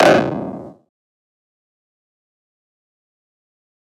フリー効果音：ドア
ドアを開けた時の音を作ってみました！城門や重い扉を開けるときに聞こえるあの音に仕上げました！
door.mp3